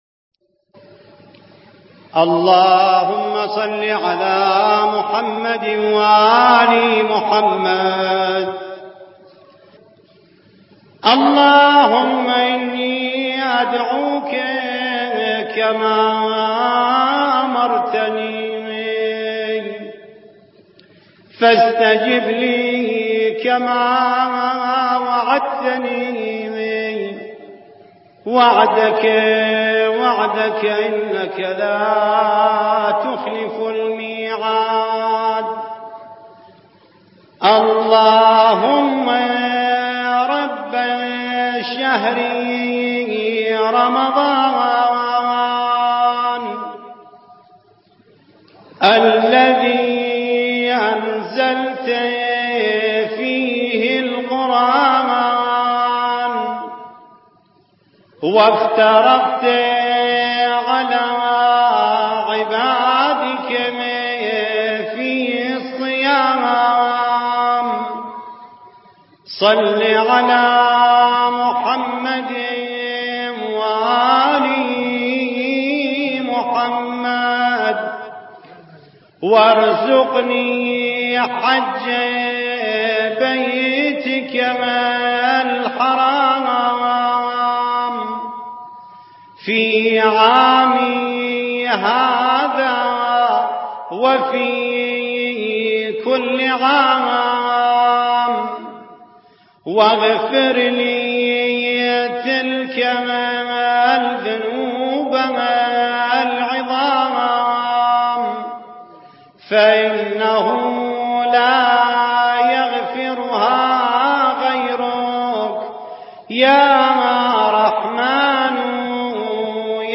دعاء اللهم رب شهر رمضان